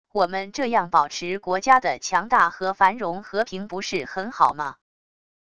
我们这样保持国家的强大和繁荣和平不是很好吗wav音频生成系统WAV Audio Player